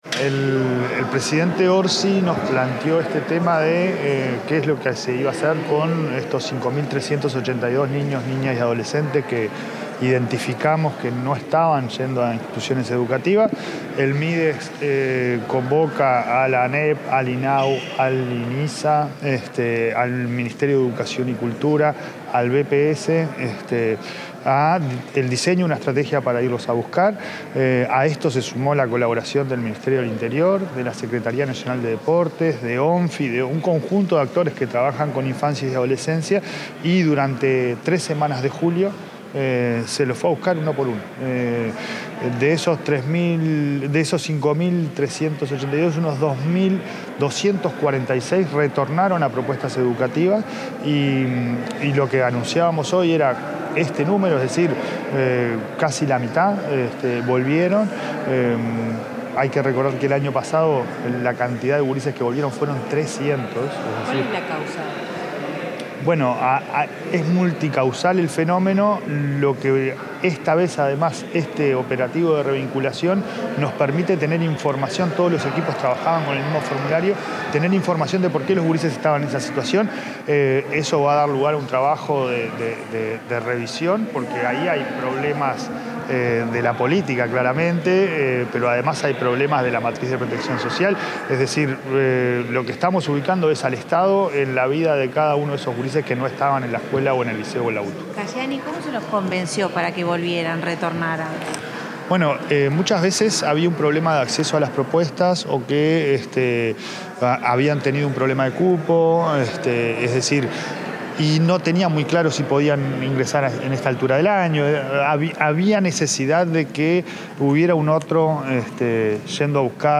El presidente de la Administración Nacional de Educación Pública (ANEP), Pablo Caggiani, dialogó con la prensa sobre los principales datos relevados